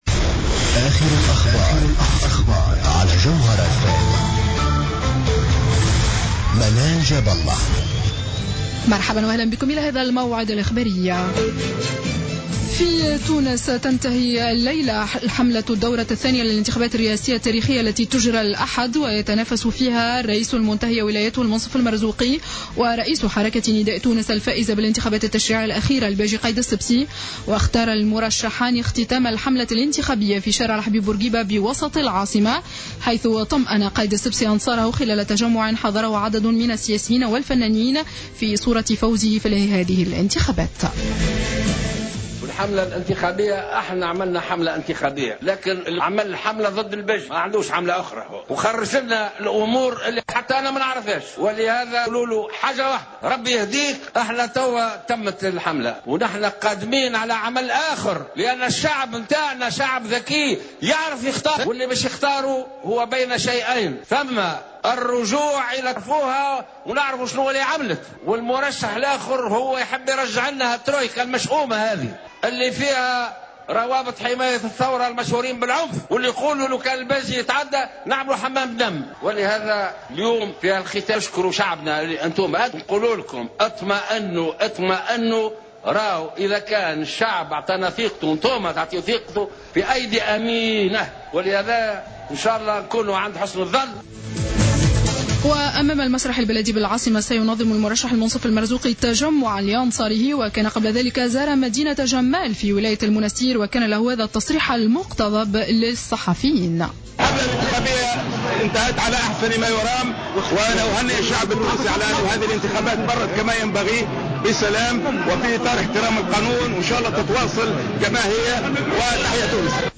نشرة أخبار السابعة مساء ليوم الجمعة 18-12-14